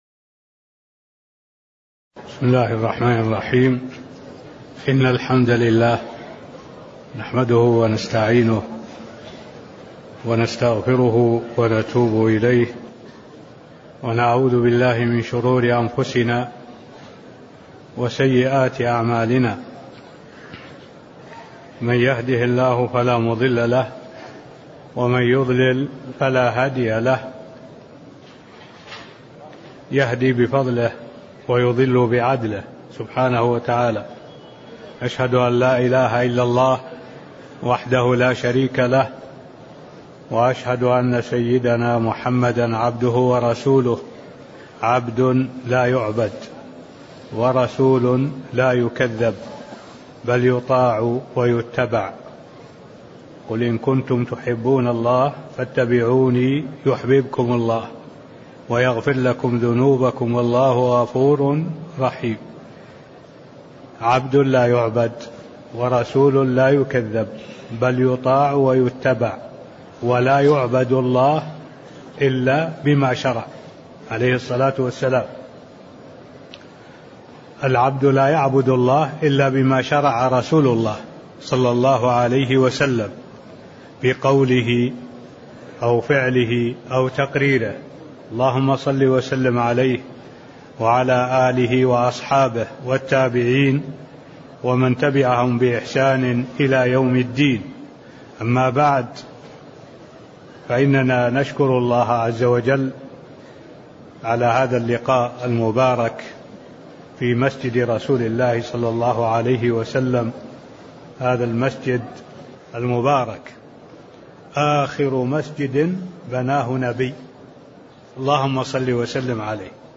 المكان: المسجد النبوي الشيخ: معالي الشيخ الدكتور صالح بن عبد الله العبود معالي الشيخ الدكتور صالح بن عبد الله العبود باب الإحرام والتلبية من قوله: والإحرام لغة (05)